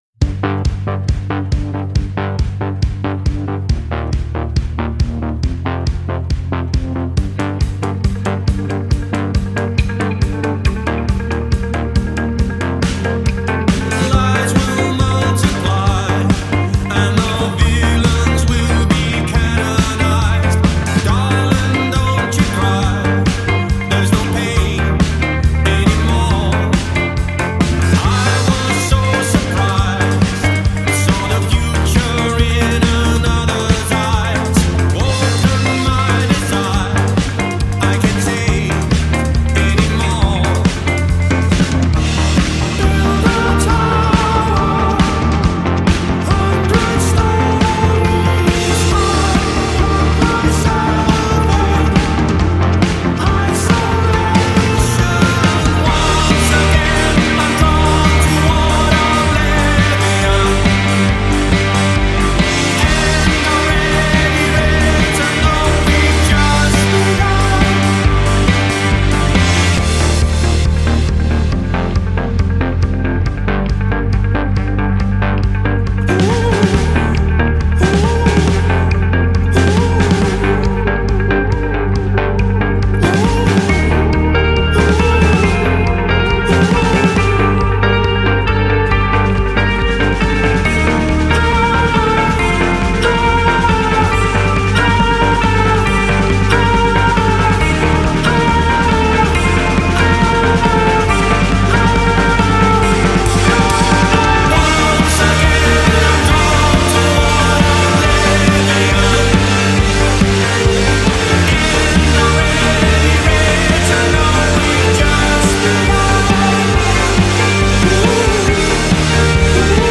BPM134-144
Audio QualityPerfect (Low Quality)